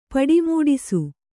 ♪ paḍi mūḍisu